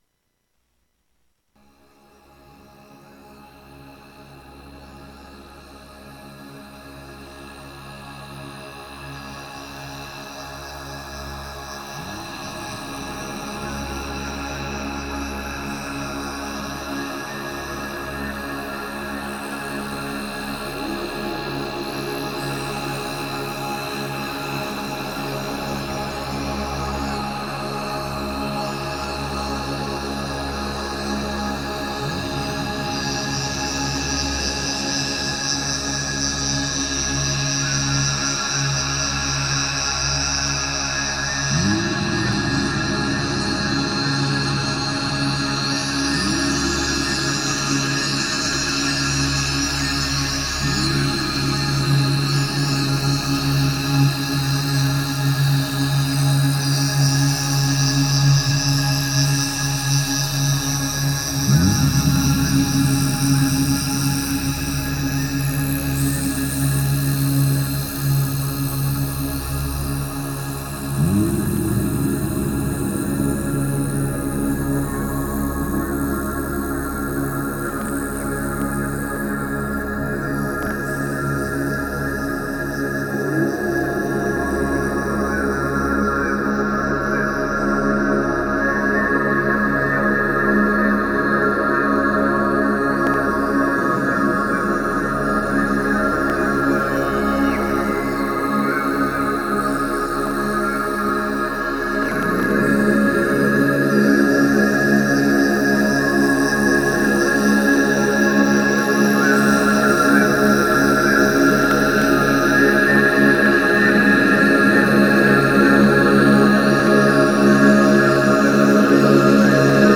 Moines-Tibetains-chants-sacrés.mp3